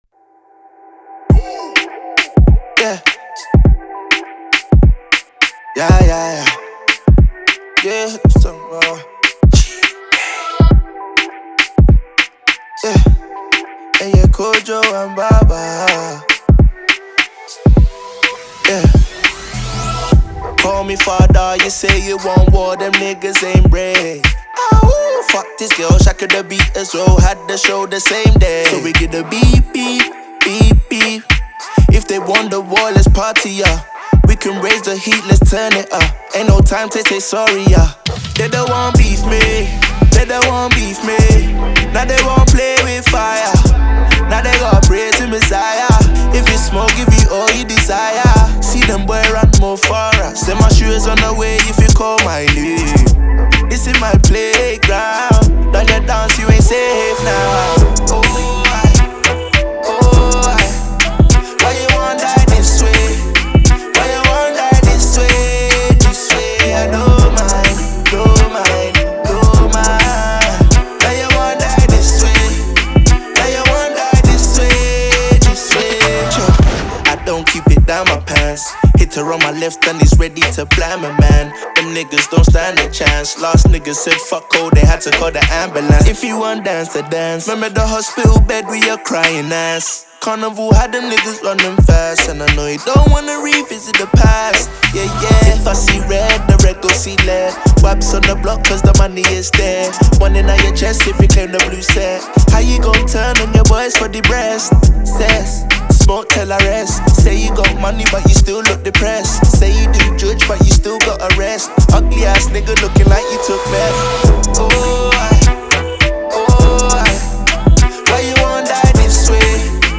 we haven’t heard much solo music from UK rapper